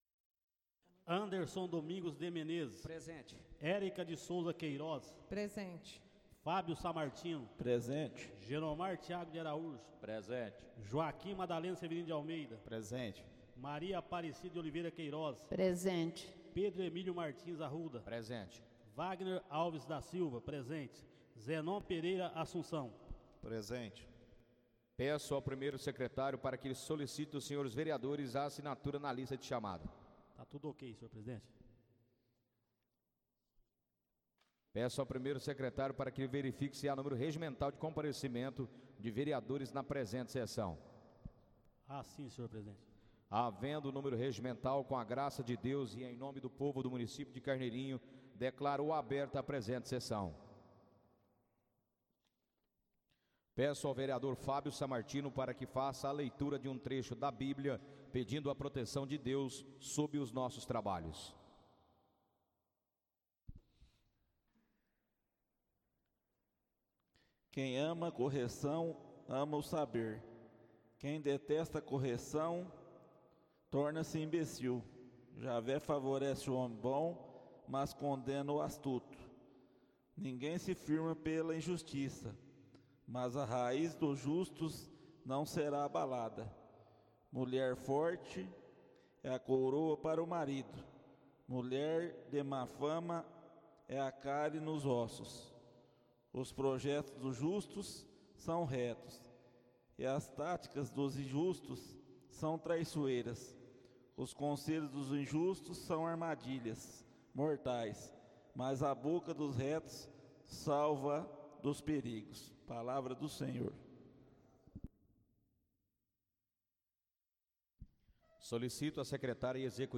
Áudio da 1.ª reunião ordinária de 2024, realizada no dia 5 de Fevereiro de 2024, na sala de sessões da Câmara Municipal de Carneirinho, Estado de Minas Gerais.